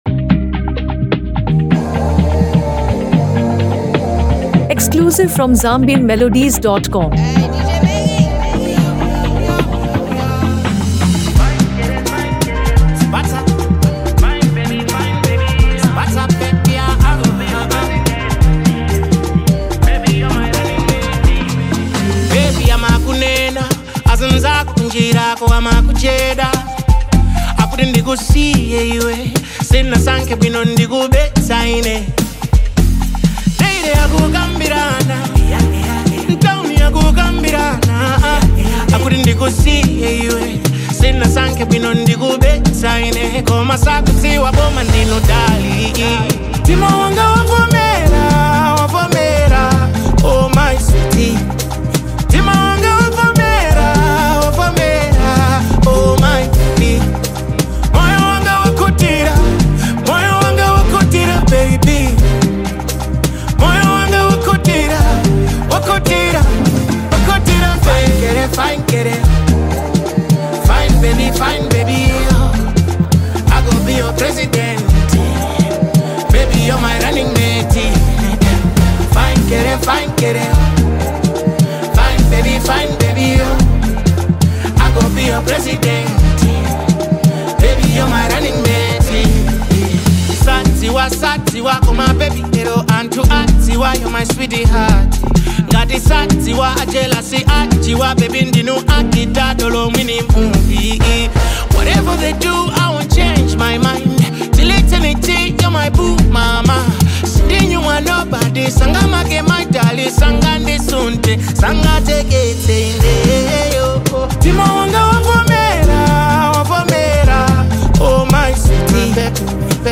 love song
soothing vocals and captivating melodies